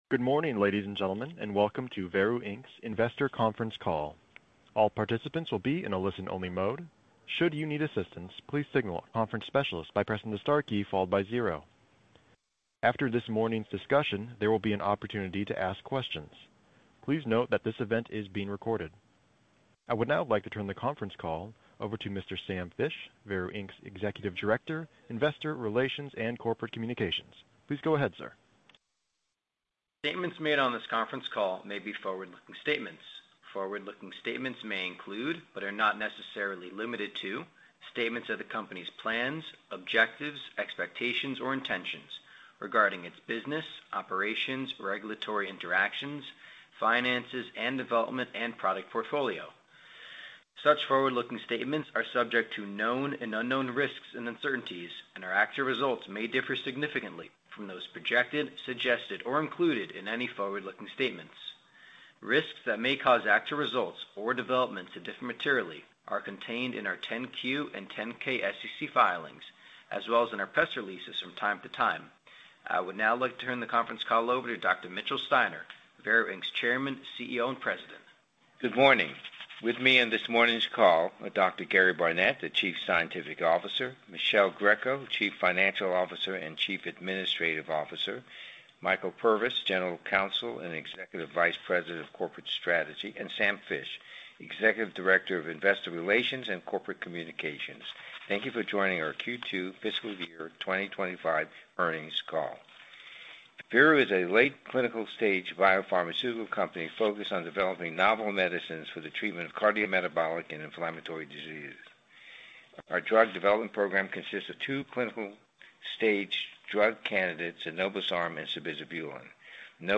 FY 2025 Q2 Earnings Conference Call